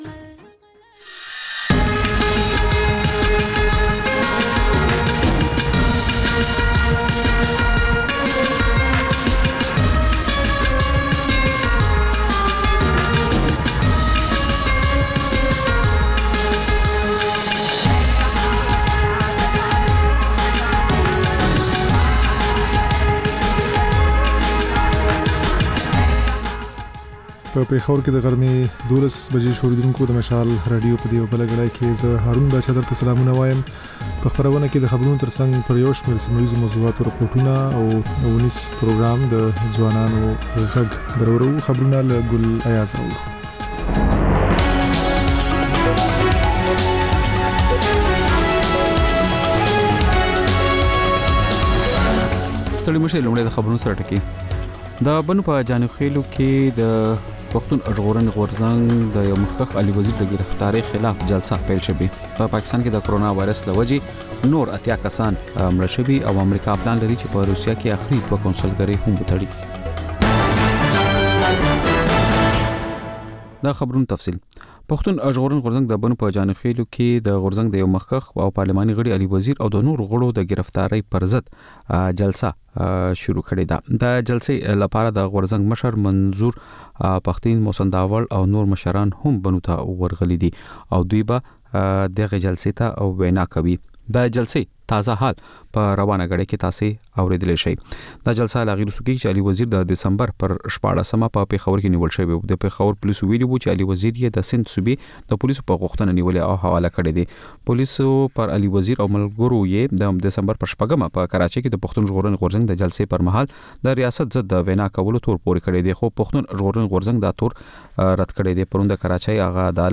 د مشال راډیو د نهه ساعته خپرونو لومړۍ خبري ګړۍ. په دې خپرونه کې تر خبرونو وروسته بېلا بېل سیمه ییز او نړیوال رپورټونه، شننې، مرکې، رسنیو ته کتنې، کلتوري او ټولنیز رپورټونه خپرېږي.